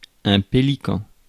Ääntäminen
IPA : /ˈpɛl.ɪ.kən/